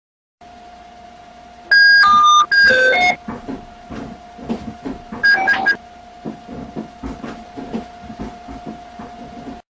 Play, download and share akbil original sound button!!!!
akbil-sesi-iett.mp3